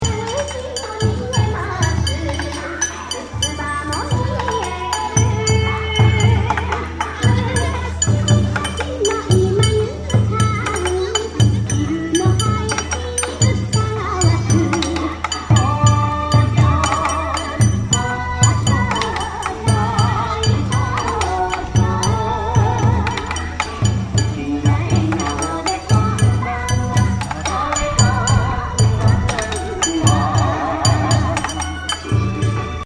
Last night we went to the Summer festival of this town called “Bon Festival”.
We have some Bon Odori (Odori means dance) with some traditional music.